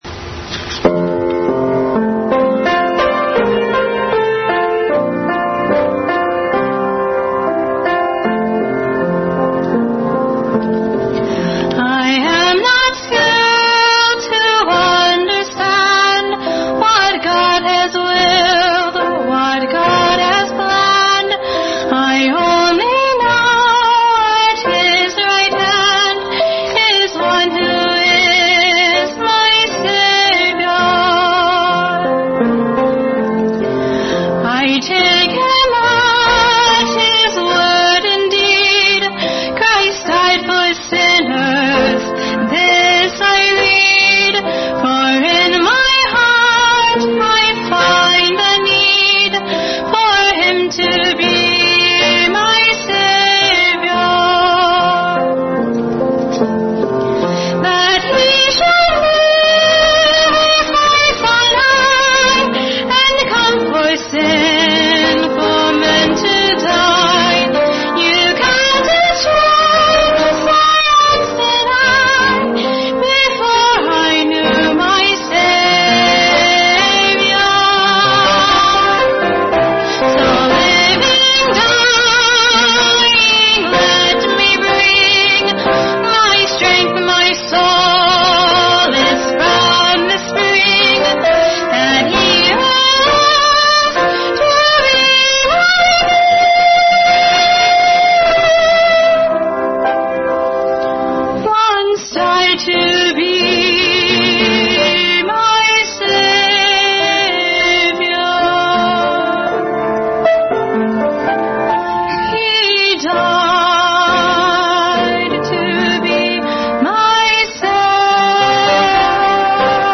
Bible Text: John 13:1-11, Numbers 19:1-22, 1 John 1:3-10, Hebrews 9:6-14 | Special Music – “One Who Is My Savior”
Service Type: Family Bible Hour